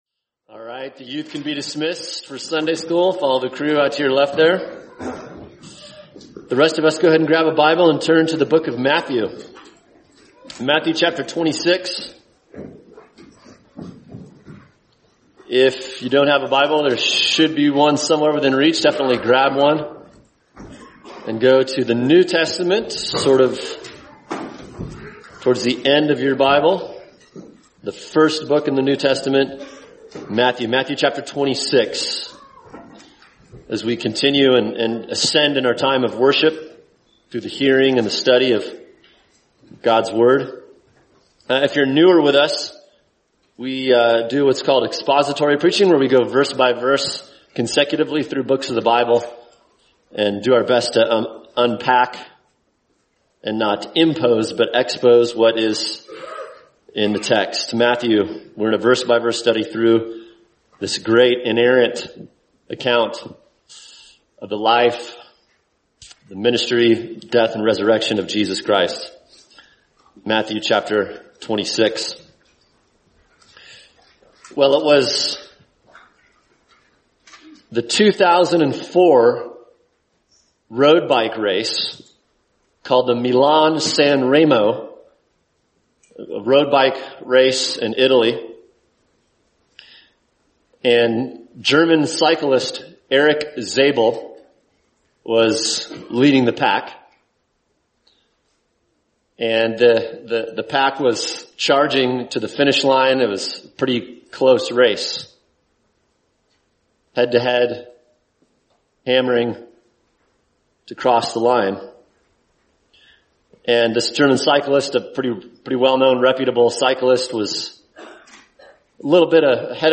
[sermon] Matthew 26:30-35 – An Unwavering Savior for Wavering Disciples | Cornerstone Church - Jackson Hole